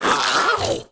Index of /App/sound/monster/skeleton_soldier_spear
dead_1.wav